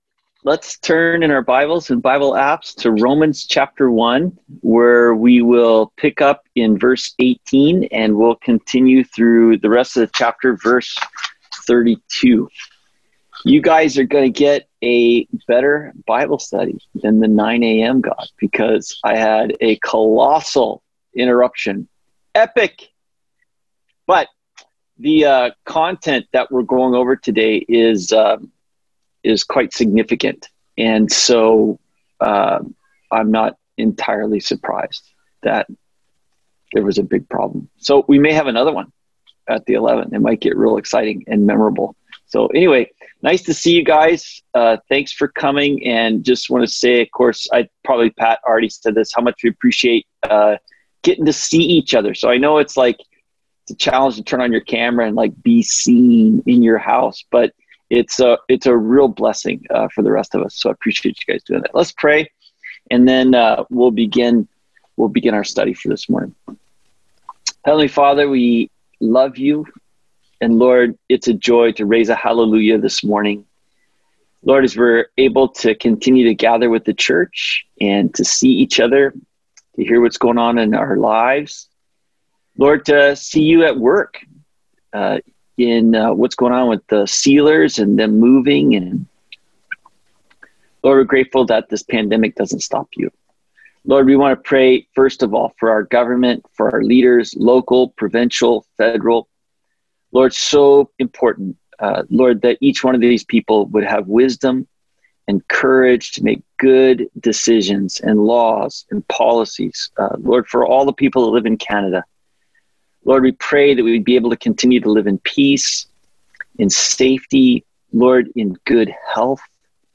Sermon-April-26.mp3